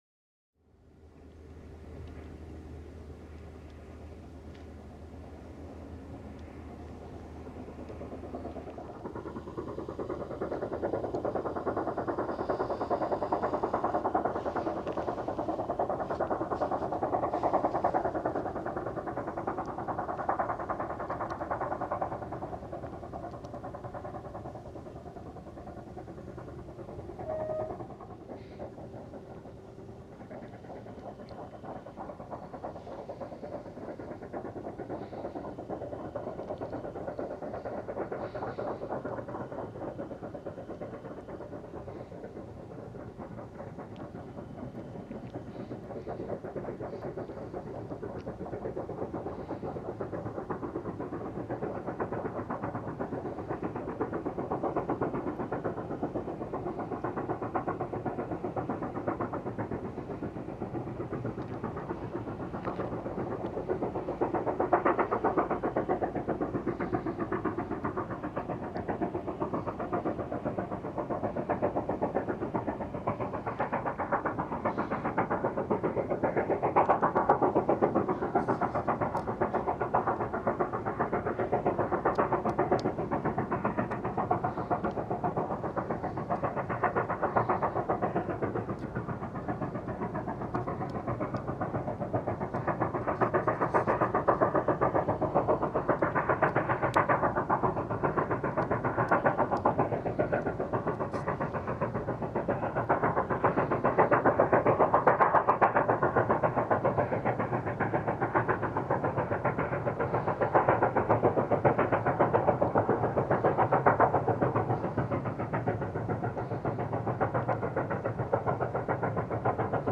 Sandaoling: Ein beladener Kohlezug erklimmt die Steigung auf der Strecke hinaus aus dem Tagebau.